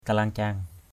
/ka-la:ŋ-ca:ŋ/ (d.) hạch = ganglion.